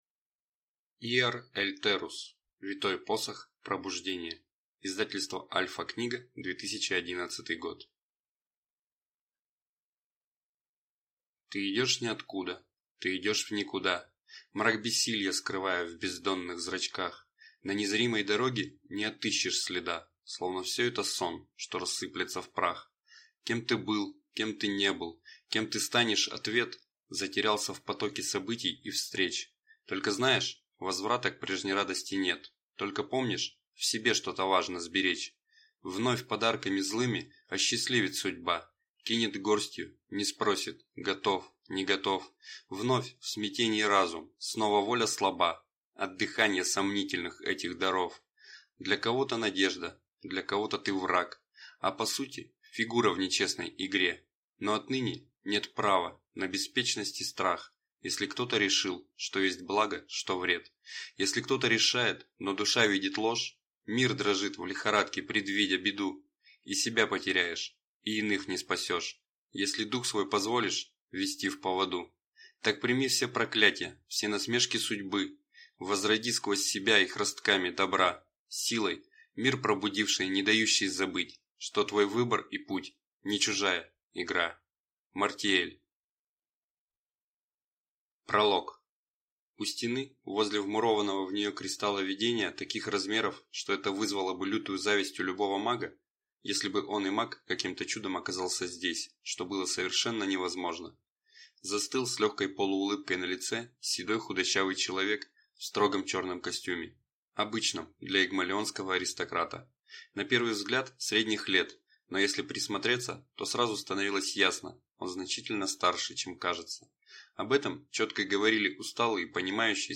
Аудиокнига Витой Посох. Пробуждение | Библиотека аудиокниг